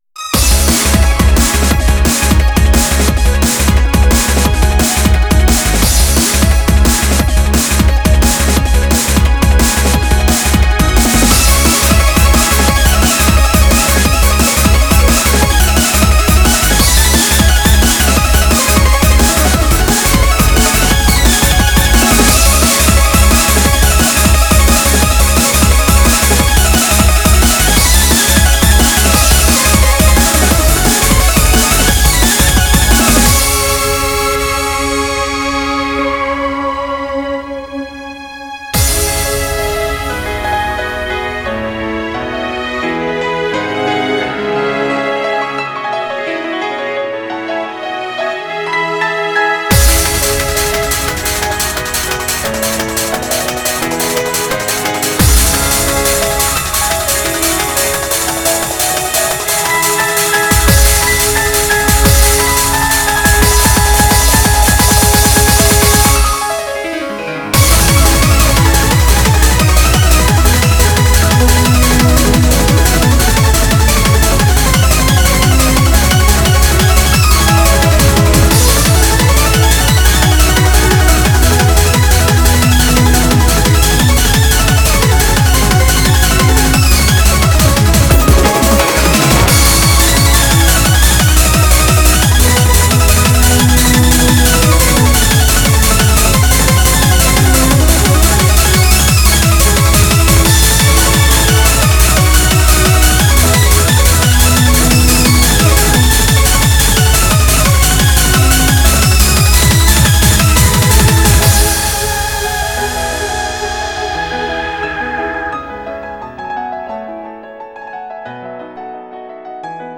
BPM175